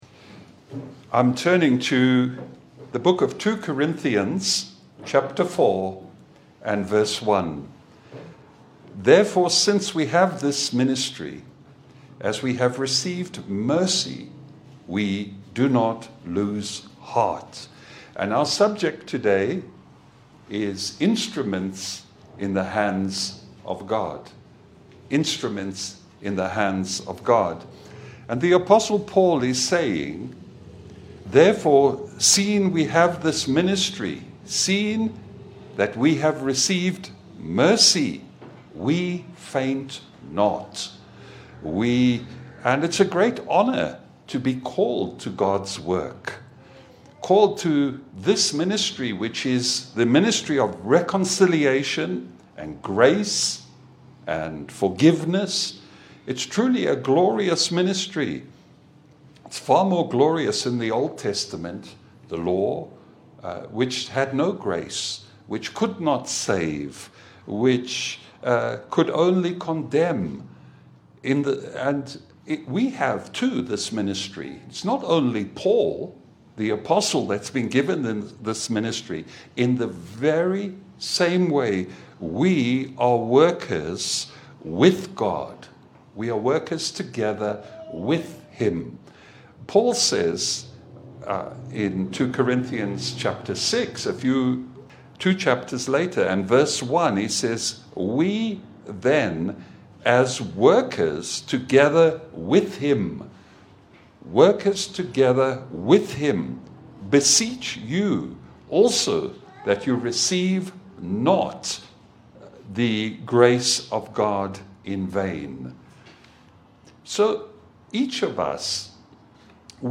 Service Type: Sunday Bible fellowship